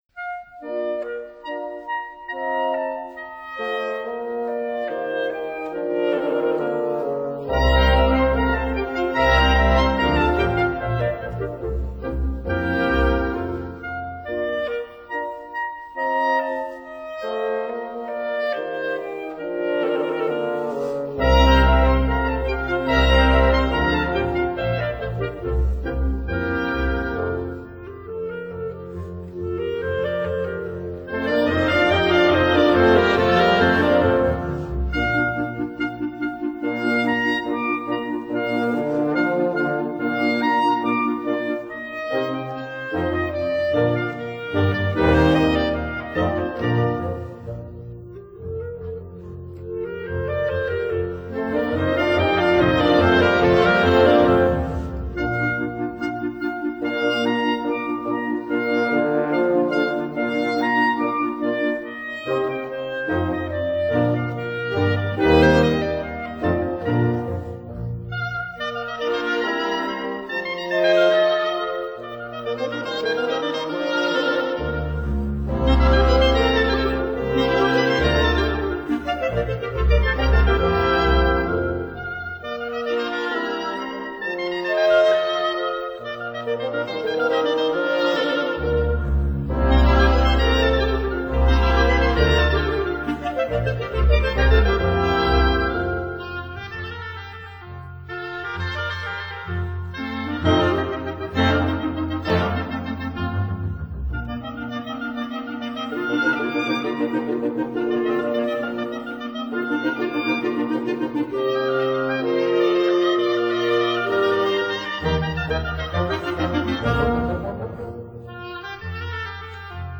(Modern Instruments.....Yuck!)